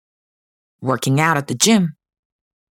ˈwɝː kɪŋ aʊt ət ðə ʤɪm